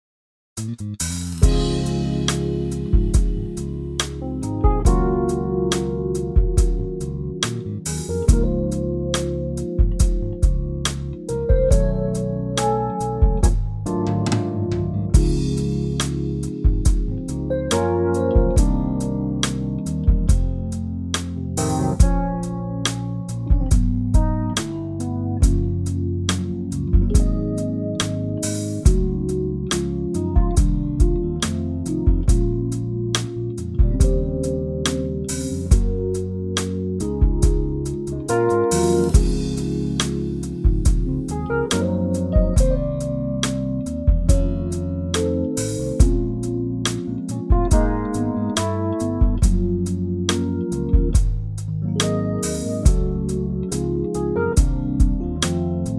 Unique Backing Tracks
key - Db - vocal range - Bb to Db
Wonderful smooth Trio arrangement
Rhodes, electric bass and classic RnB kit and great playing!